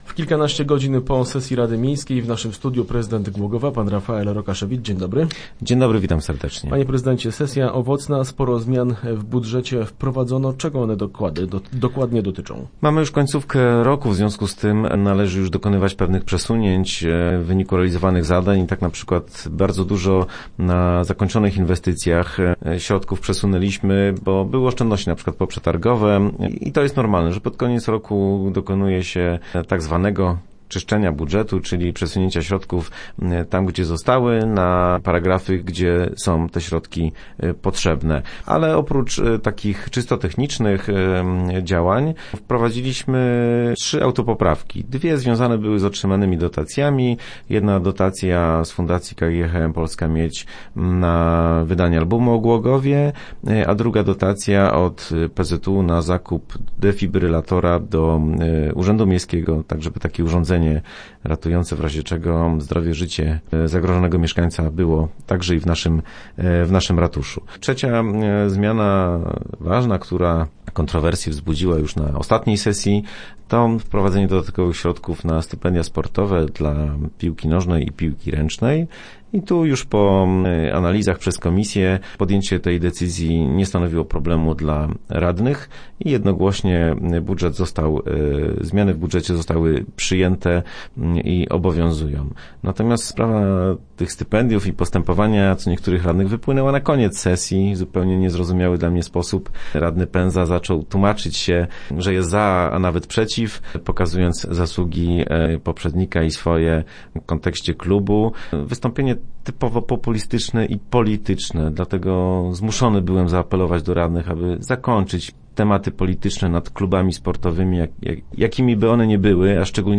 1125rokire.jpgZ końcem roku miejscy radni wprowadzili kilka zmian w budżecie oraz podjęli ważne dla mieszkańców uchwały. W radiowym studiu mówił o tym prezydent Głogowa Rafael Rokaszewicz.